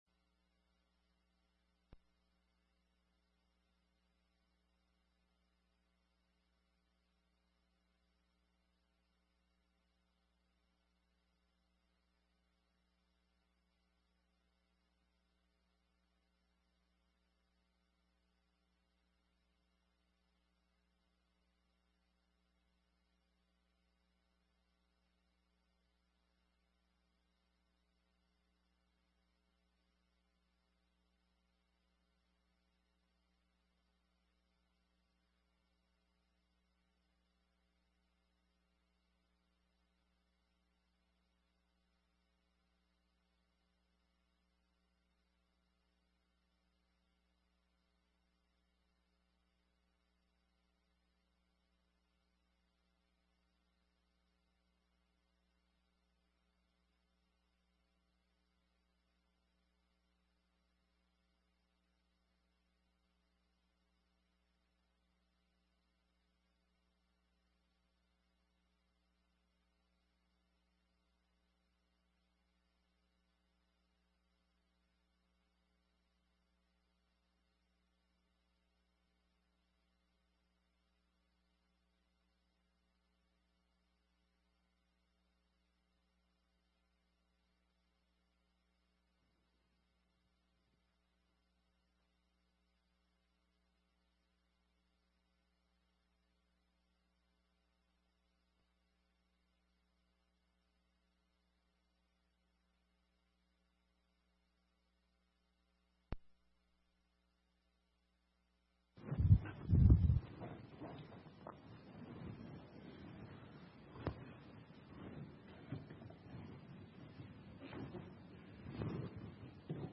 This sermon was given at the Bend-Redmond, Oregon 2017 Feast site.